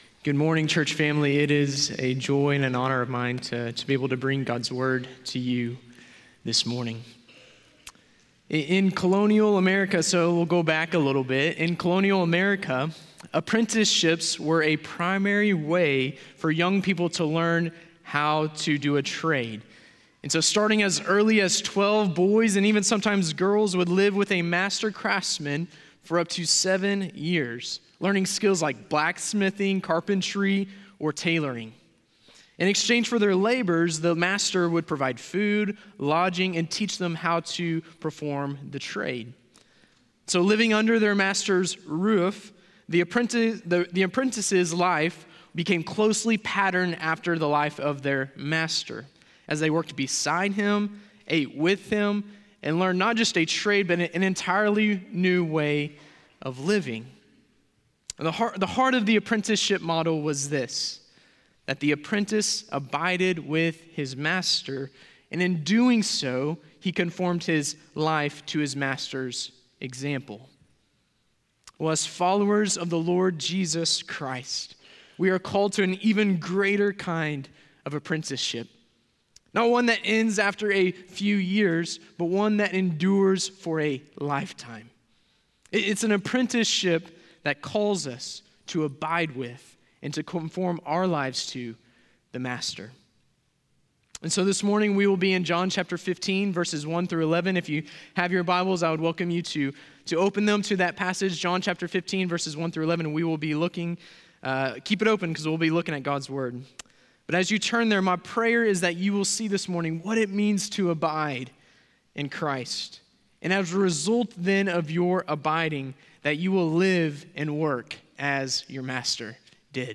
John 15:1-11 Guest Preacher: